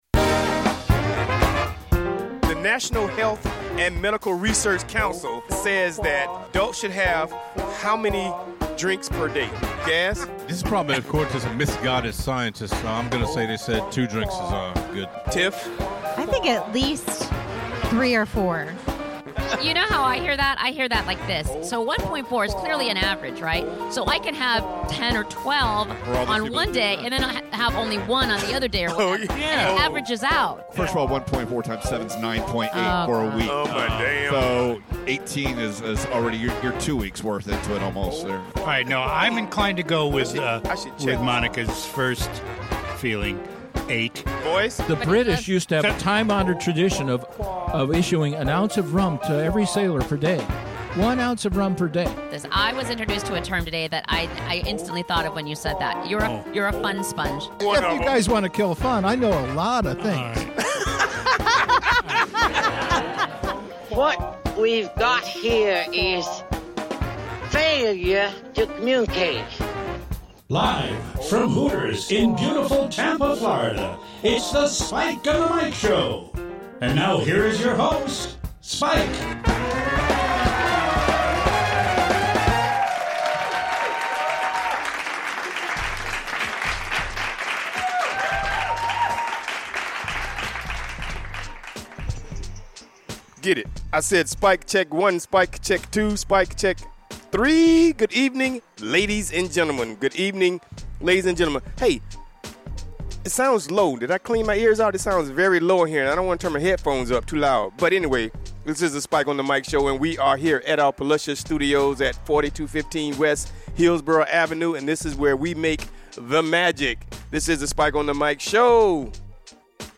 Airs Live from Hooters Tampa Mondays at 7pm ET